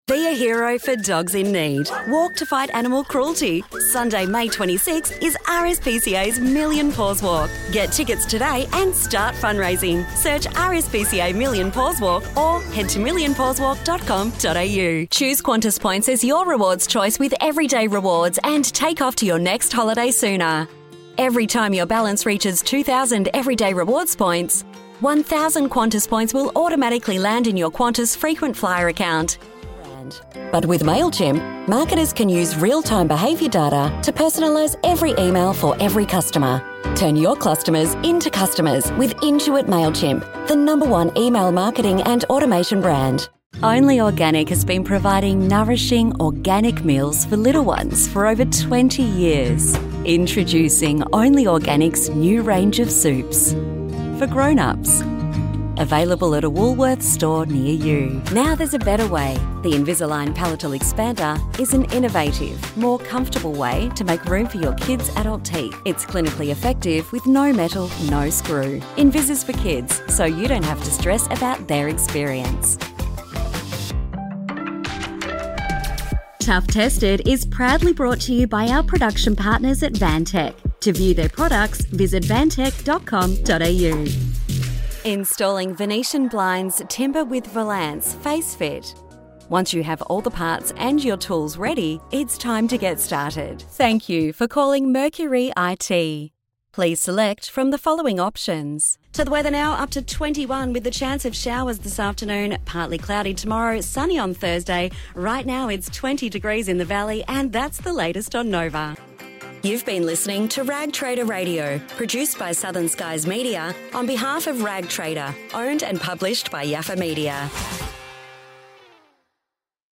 Hear the lively, inviting voices used in Macy’s advertising across retail campaigns.
0208General_Voiceover_Demo.mp3